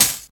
60 HAT 2.wav